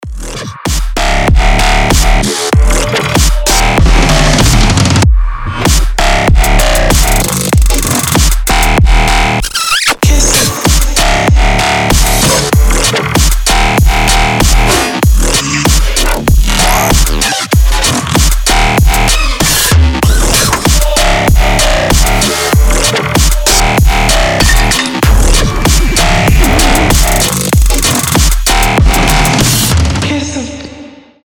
• Качество: 320, Stereo
жесткие
мощные басы
Trap
Midtempo
Bass House
взрывные
Trapstep
очень громкие
жирный бас
Басисто - не то слово!